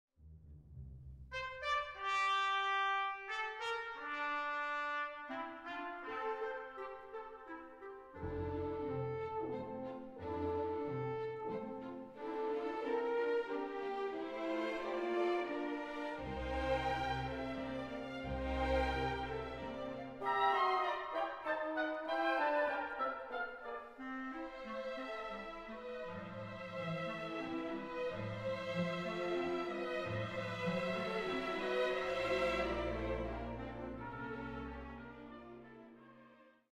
Świetne, koślawe i groteskowe solo skrzypiec: